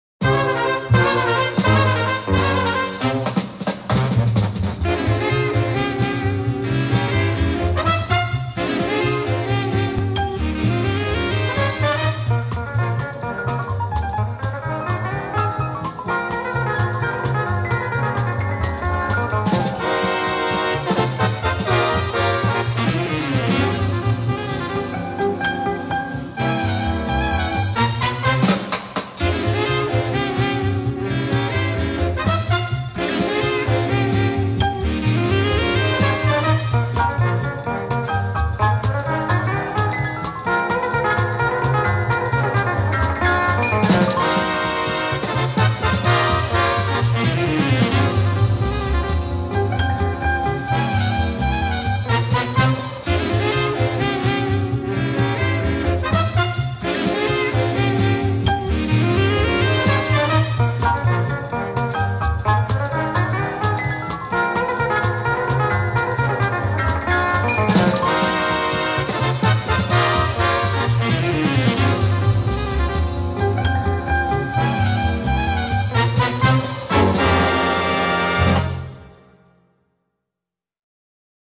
• Качество: высокое
Увертюра